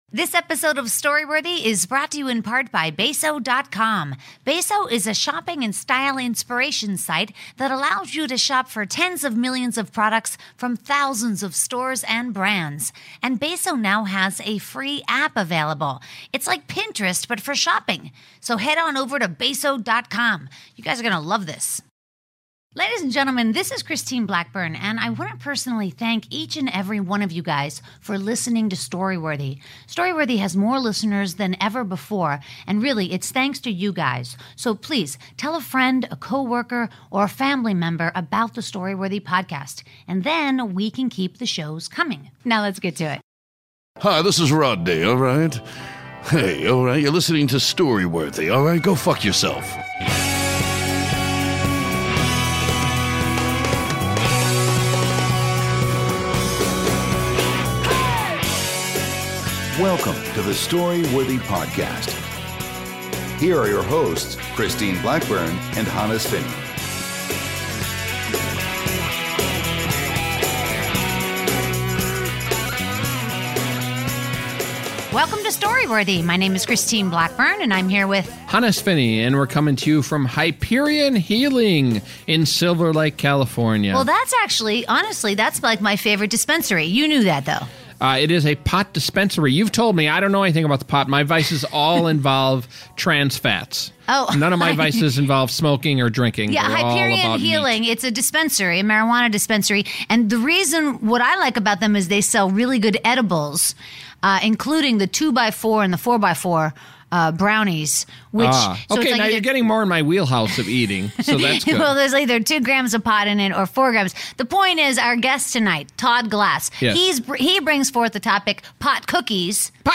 Grab your favorite edible and join us for this unforgettable, jingle-filled Story Worthy!